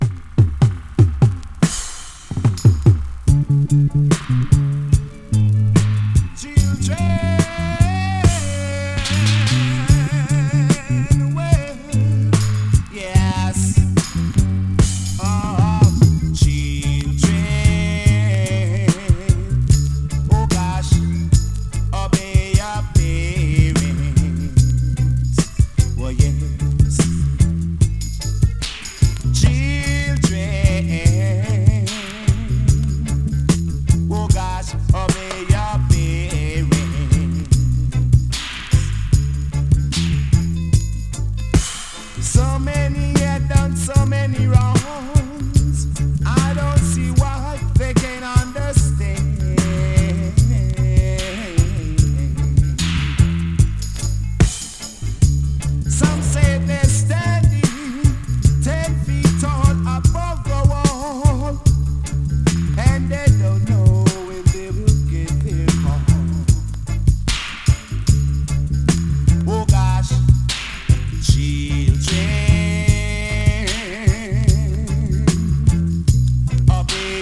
tuff vocal